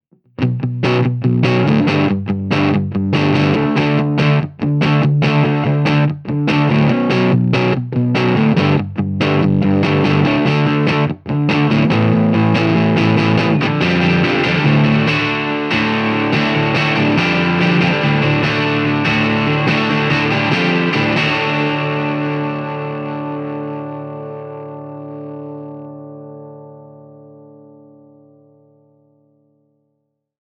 JTM45 Dirty Marshall G10
If the G10's had a little better definition I probably would have gone with those but they're a little too muddy overall.
What makes this clip difficult is some speakers work well with the PM's in the first half while others handle the open chord work much better.
JTM_DIRTY_MarshallG10.mp3